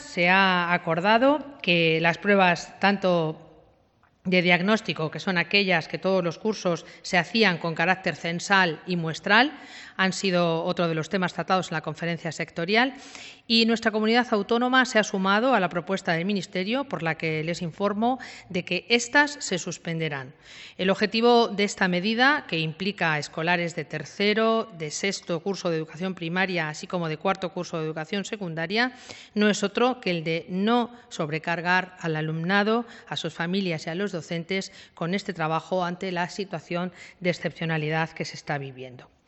(DIRECTO) Comparecencia informativa de Consejo de Gobierno Extraordinario